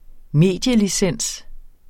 Udtale [ ˈmeˀdjəliˌsεnˀs ]